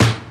Snare